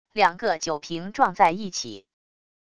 两个酒瓶撞在一起wav音频